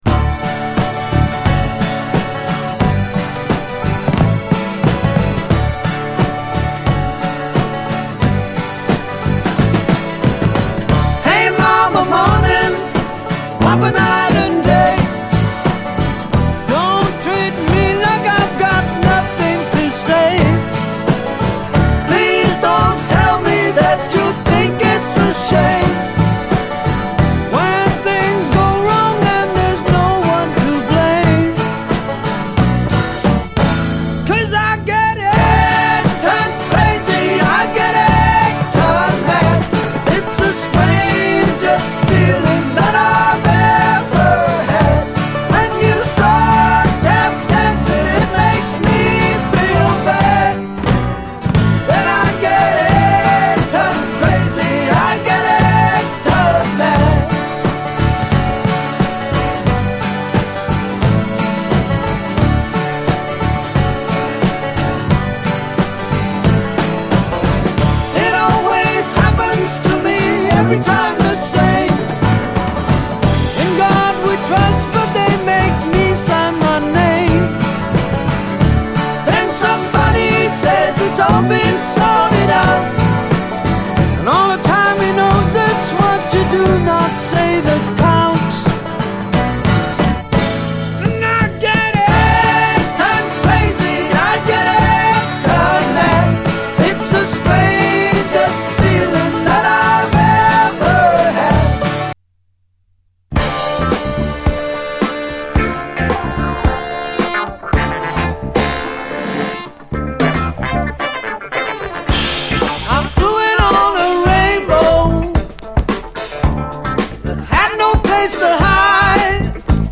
70's ROCK, SSW / AOR, ROCK